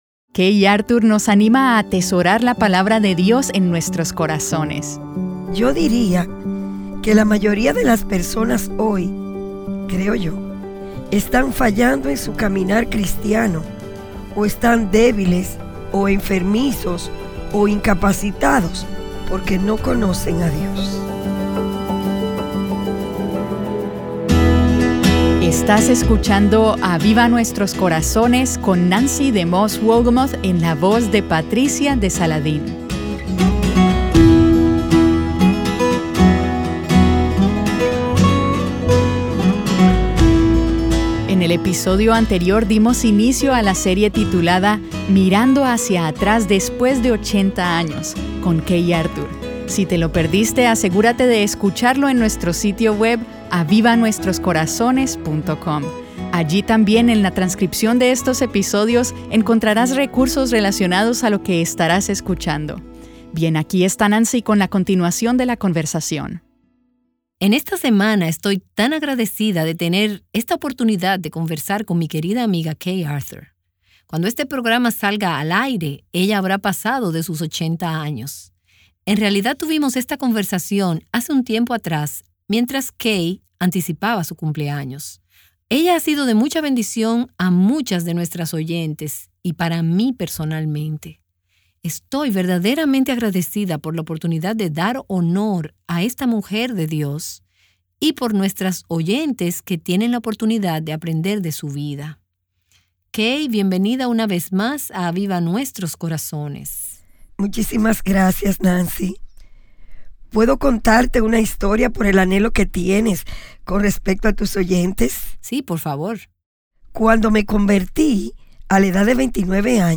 Entrevista con Kay Arthur